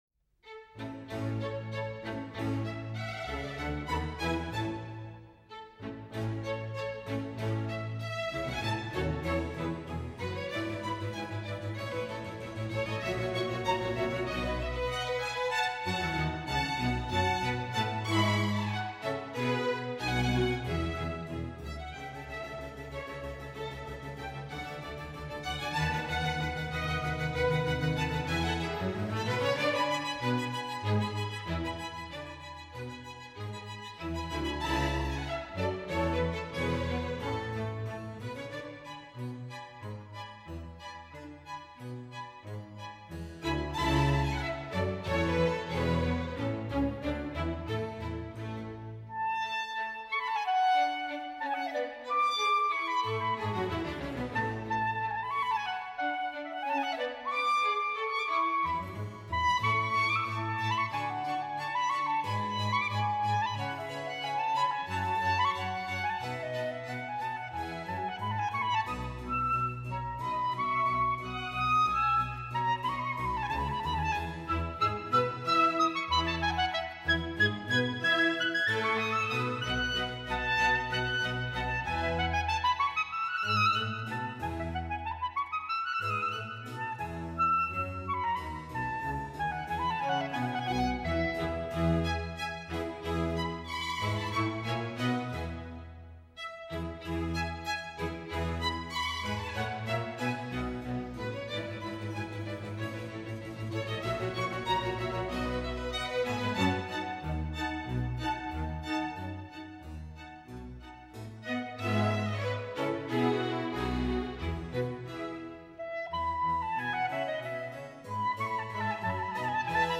late-Baroque